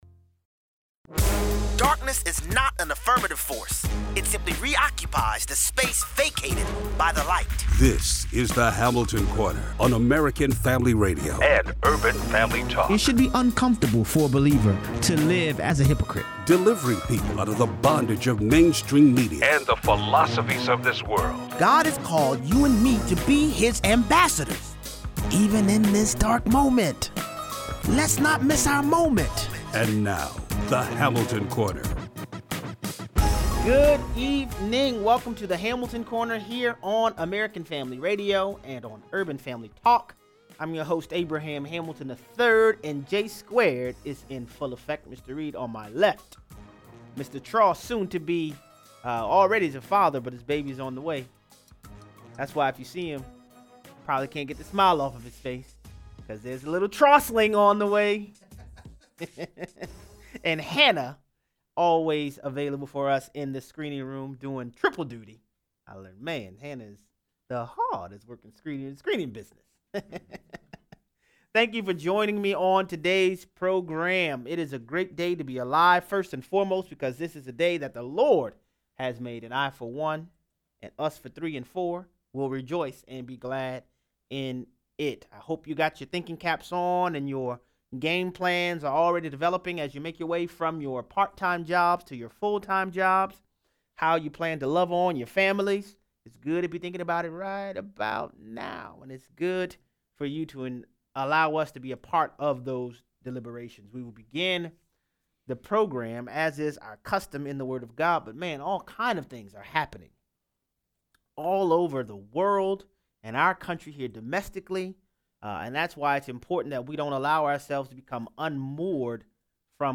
Our value is determined by The One who has called, The Caller. 0:23 - 0:40: Great News! Jim Jordan announces his run for House Speaker. The European Union blinks on tariffs. 0:43 - 0:60: Other presidents failed to try the things that seem to be producing results for President Trump. Callers weigh in.